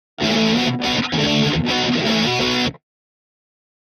Guitar Rock Finale Rhythm Version 3